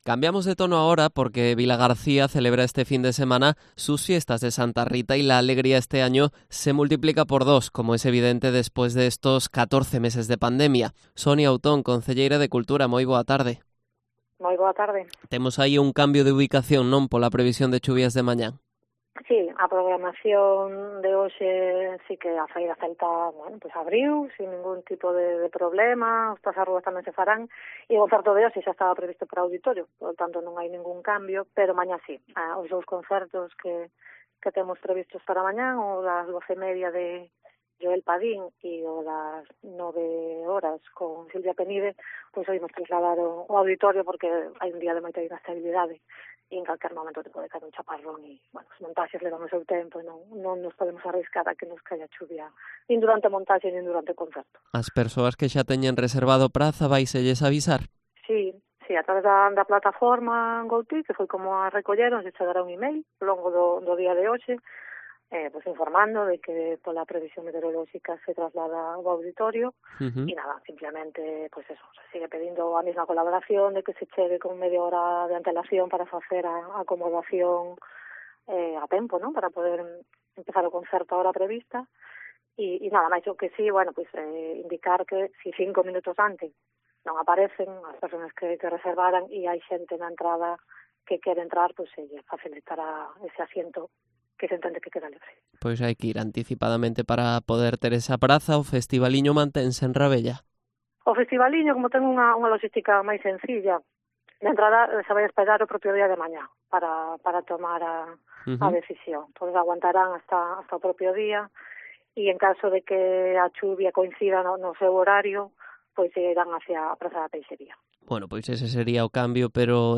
Entrevista a Sonia Outón, concelleira de Cultura de Cambados